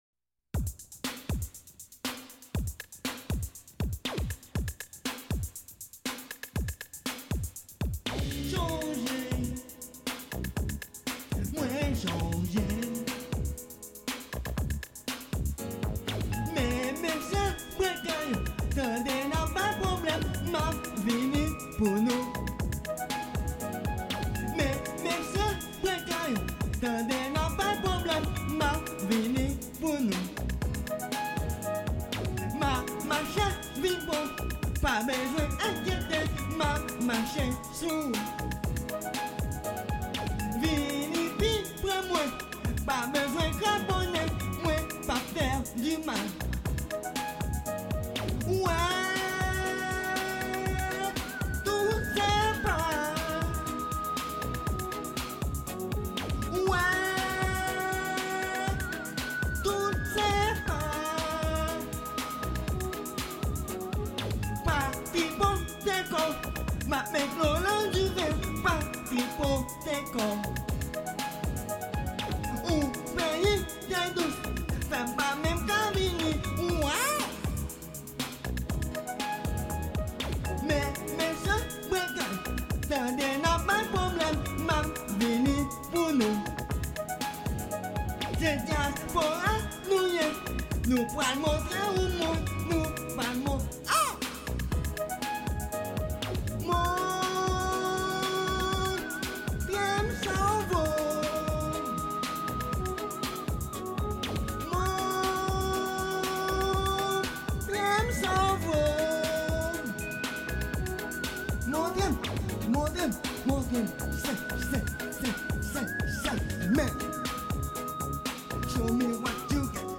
Very little is known about the man singing these songs.
Here is what has been verified: (i) the CD was recorded at least five years ago, though probably closer to ten or more; (ii) the accompaniment is very likely somebody else's; and (iii) the recording was submitted to a CD duplication service in or around Boston, Mass.
The lyrics are some kind of French glossolalia, with a bit of English thrown in arbitrarily. This is outsider music at its best: unknown, at times unnerving, and presumably unrehearsed.